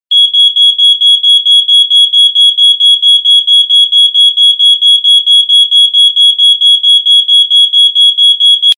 datchik-dyma_24583.mp3